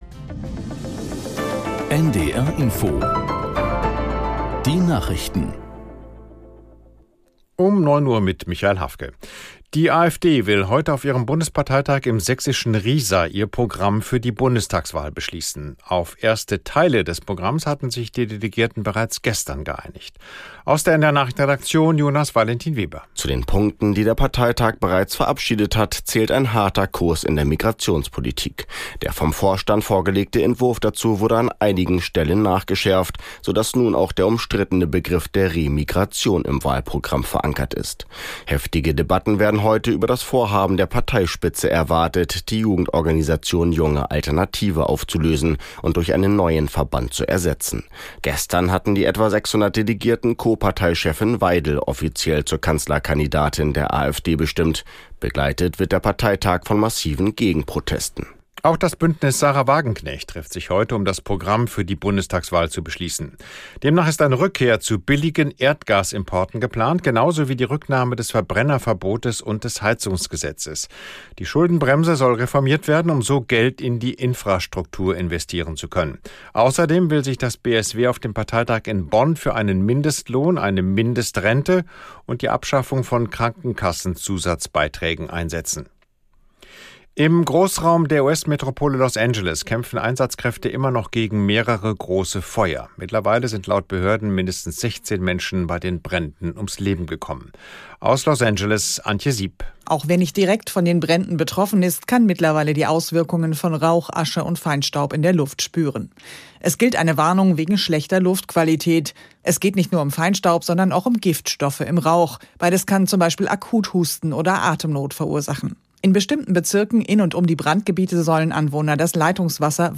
Nachrichten NDR Info Tägliche Nachrichten